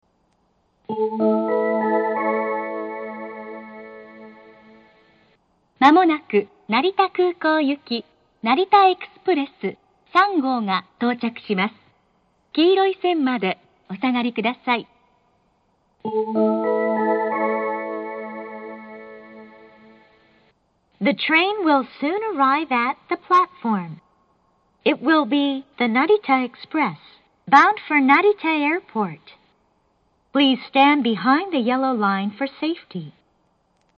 下り接近放送 成田エクスプレス３号成田空港行の放送です。